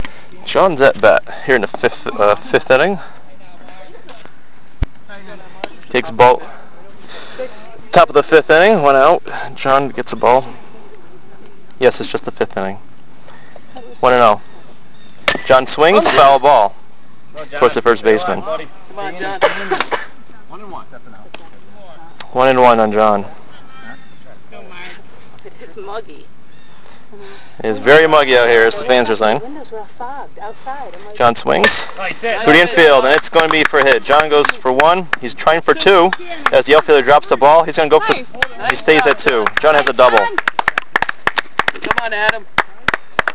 Play-by-play coverage